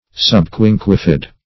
Subquinquefid \Sub*quin"que*fid\, a.